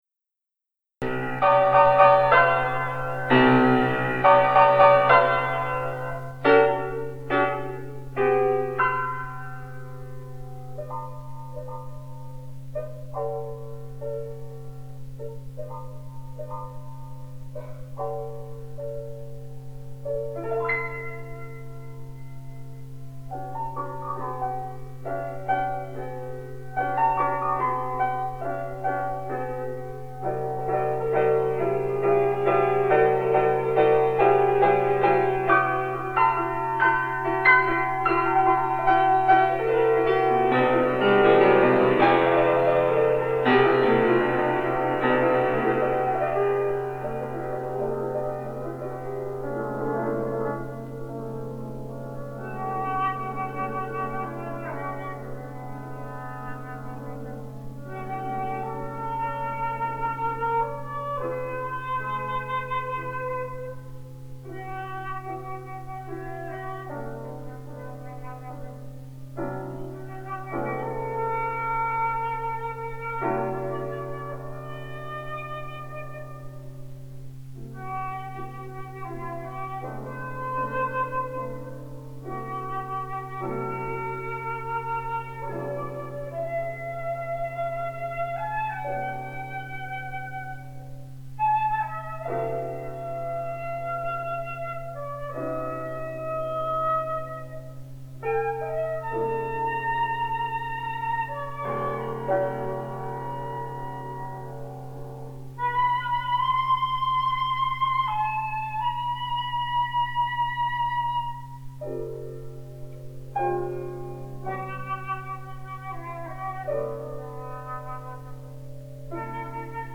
Untitled Piece for Flute and Piano (1993) Flute and Piano Duration: 5 minutes Note Composed October 1993.
Performance History: None Listen Archival Recording Score Score